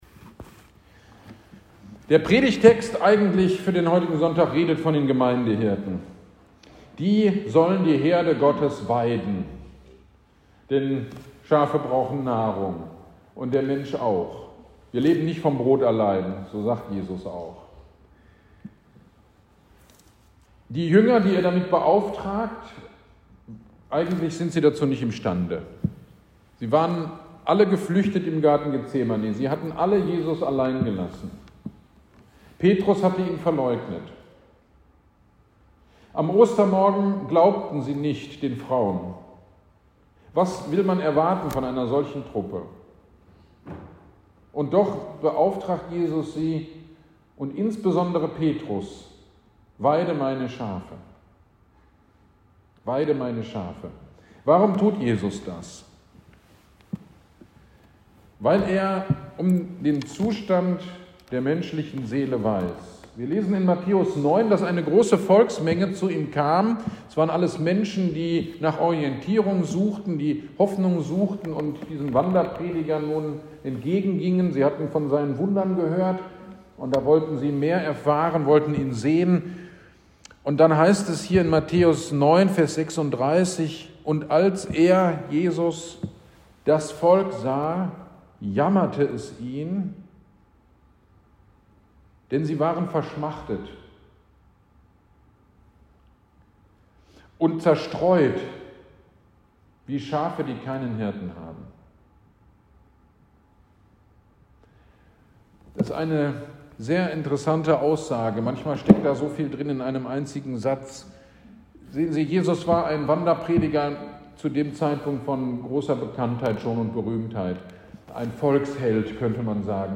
GD am 23.04.23 Predigt zu Matthäus 9.36
Predig-zu-Matthaeus-936.mp3